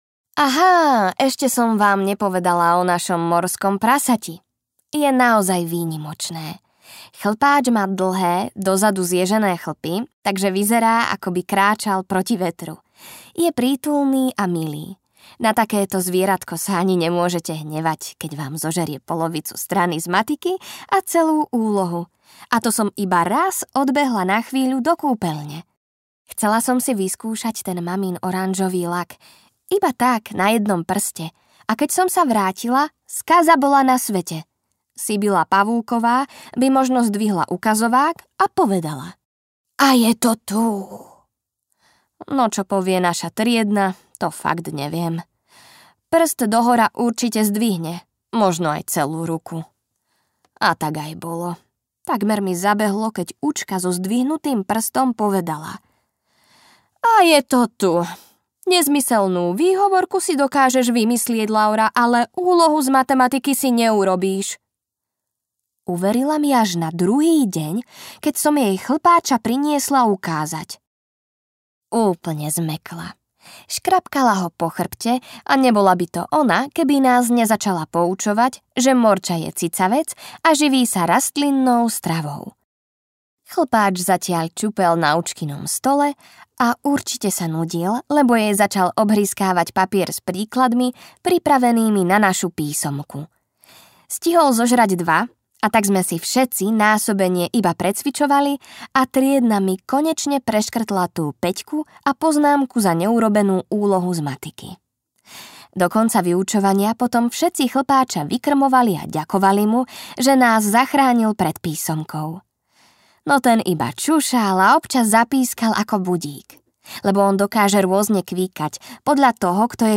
Modré srdce s kečupovým fľakom audiokniha
Ukázka z knihy